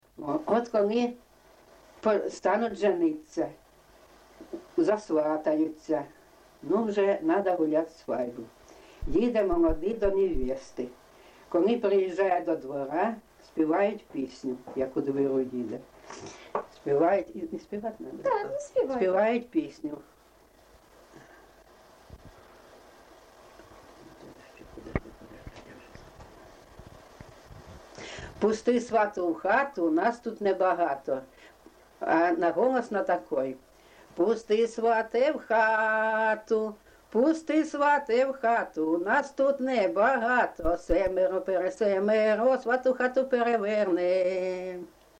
ЖанрВесільні
Місце записус. Курахівка, Покровський район, Донецька обл., Україна, Слобожанщина